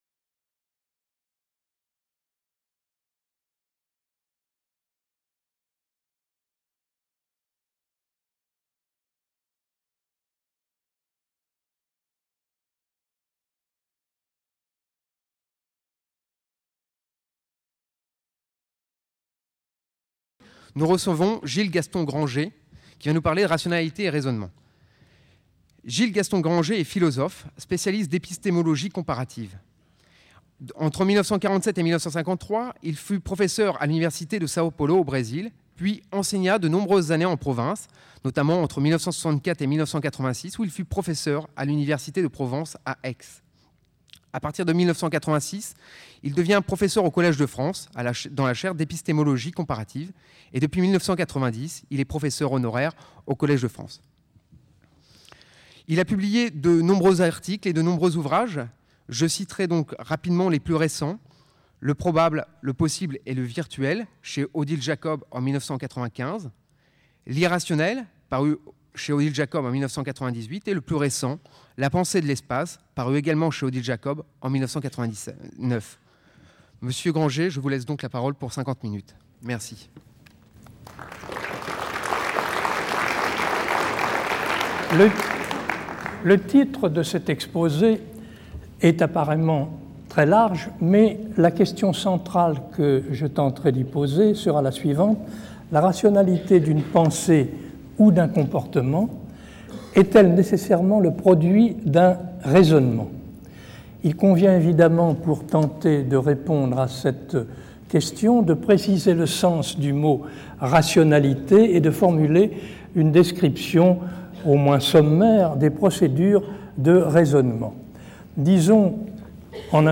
Conférence du 17 janvier 2000 par Gilles-Gaston Granger.